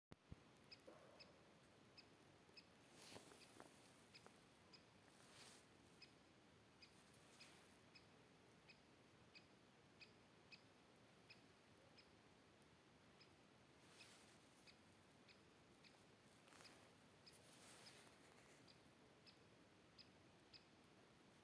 большой пестрый дятел, Dendrocopos major
Administratīvā teritorijaTukuma novads
СтатусСлышен голос, крики